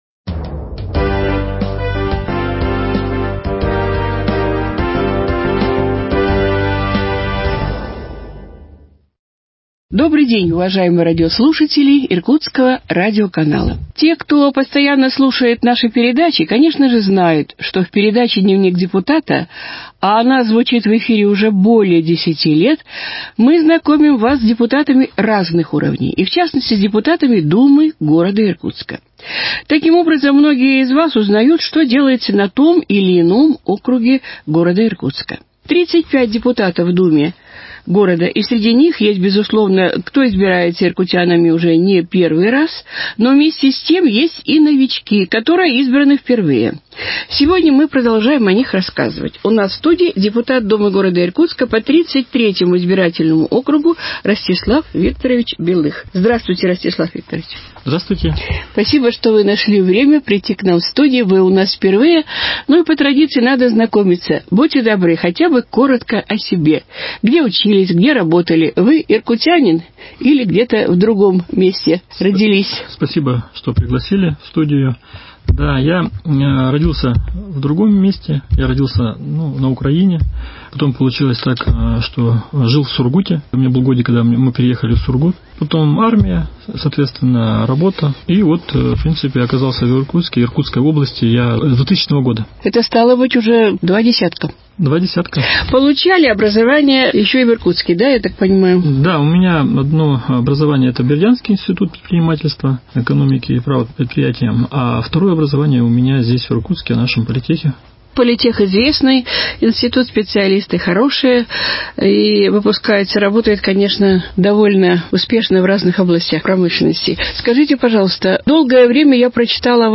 беседует с депутатом Думы Иркутска по 33-ему избирательному округу Ростиславом Белых.